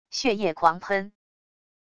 血液狂喷wav音频